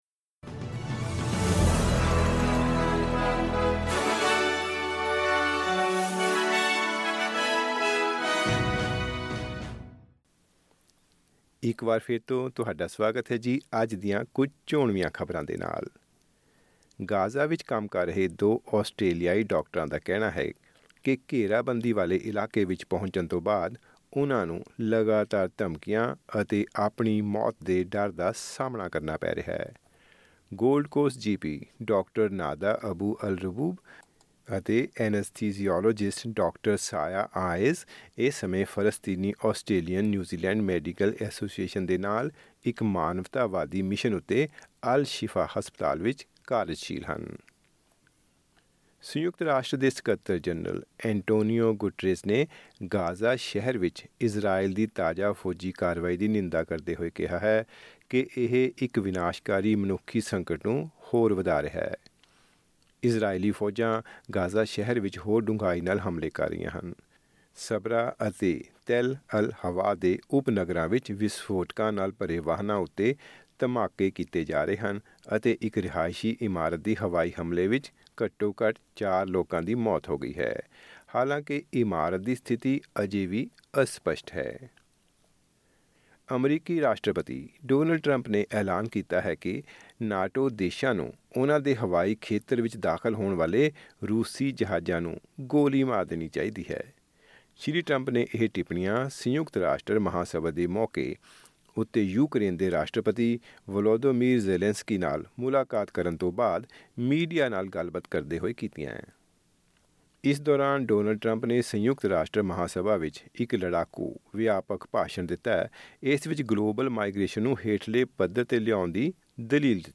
ਖਬਰਨਾਮਾਂ: NSW ਸਕੂਲੀ ਬੱਚਿਆਂ ਨੂੰ ਹੋਮ ਵਰਕ ਵਿੱਚ ਸਹਾਇਤਾ ਦੇਣ ਲਈ NSWEduChat ਸ਼ੁਰੂ